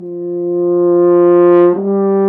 Index of /90_sSampleCDs/Roland L-CD702/VOL-2/BRS_F.Horn FX/BRS_Intervals
BRS F HRN 02.wav